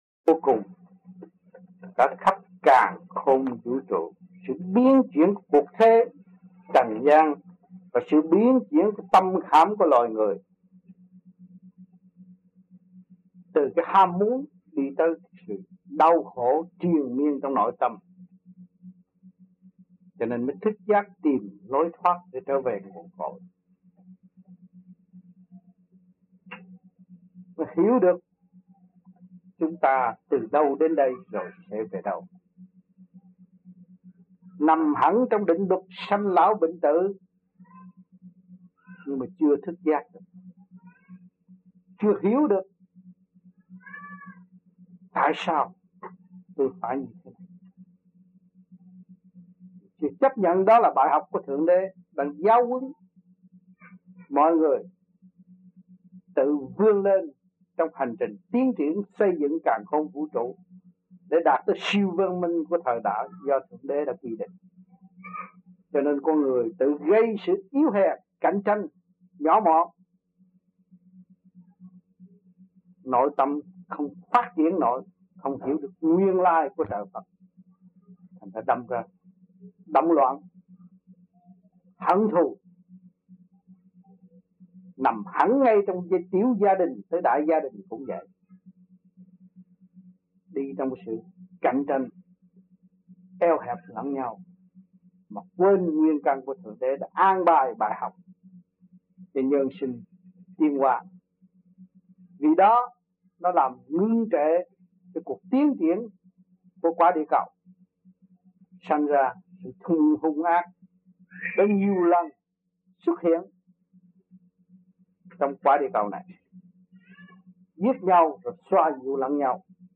1980-11-20 - AMPHION - THUYẾT PHÁP 01